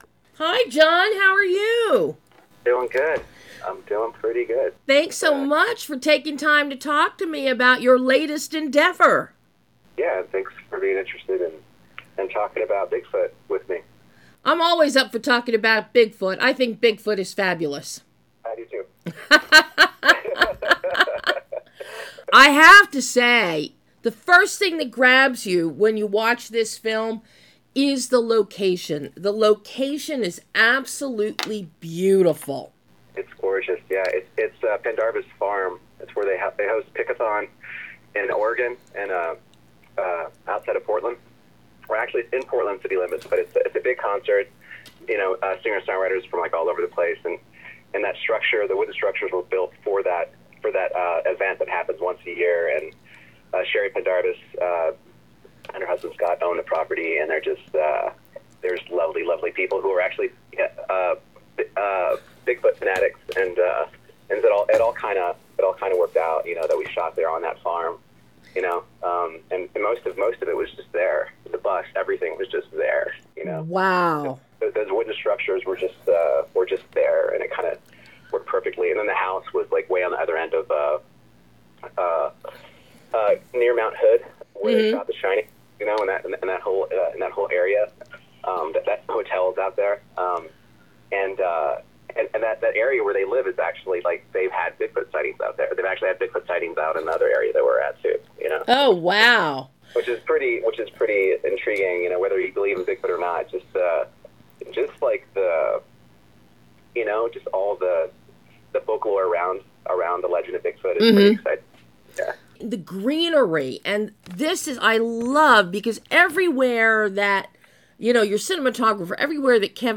I think you’ll really enjoy this interview, too.